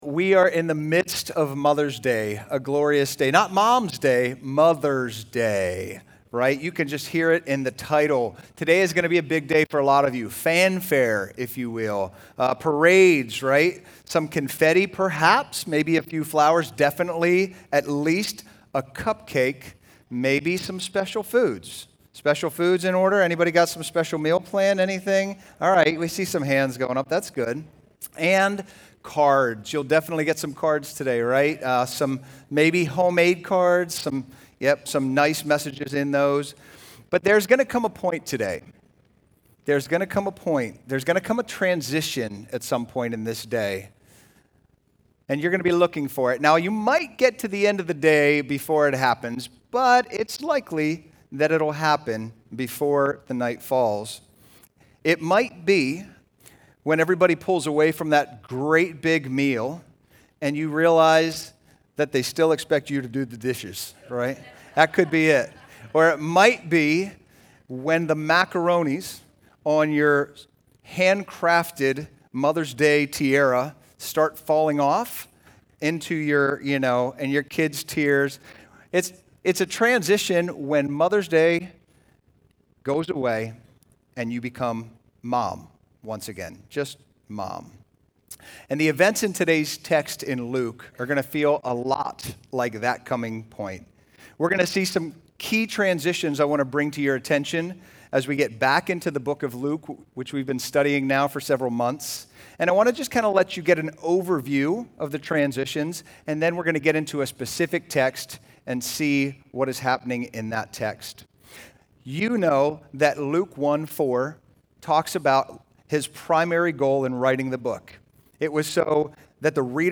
Sermons from Grace Community Church: Kingsville, MD